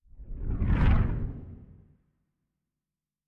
Distant Ship Pass By 4_2.wav